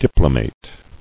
[dip·lo·mate]